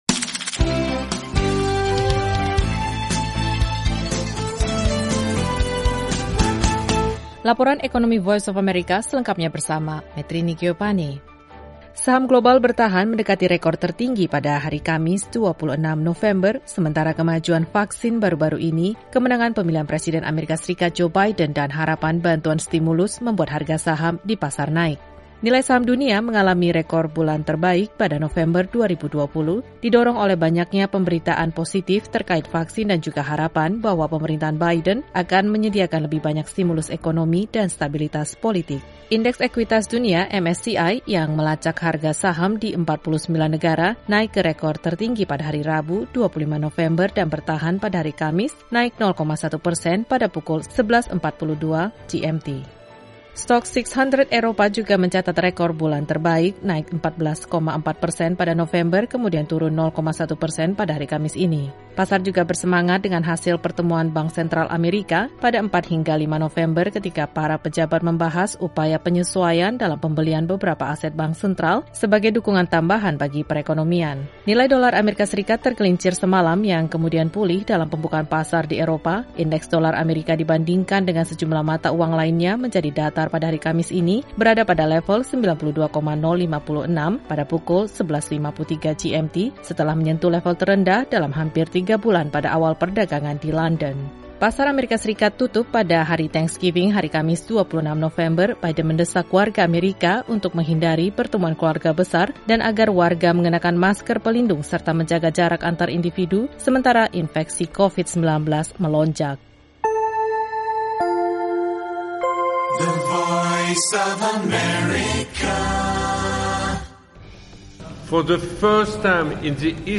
Laporan Ekonomi dan Keuangan VOA mengenai pasar saham dunia sementara pasar AS tutup pada Thanksgiving. Simak juga laporan terkait Badan Bantuan Internasional PBB (UNWRA) yang pertama kali kehabisan dana tunai dalam sejarah.